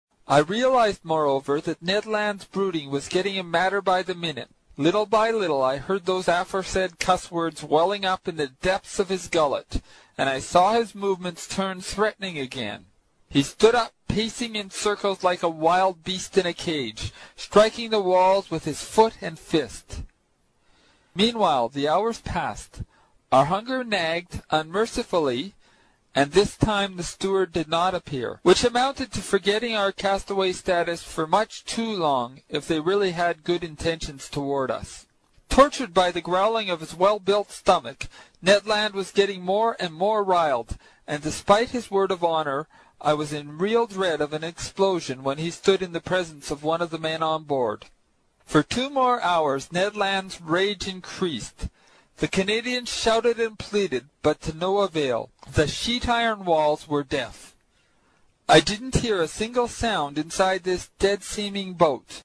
在线英语听力室英语听书《海底两万里》第127期 第9章 尼德兰的愤怒(12)的听力文件下载,《海底两万里》中英双语有声读物附MP3下载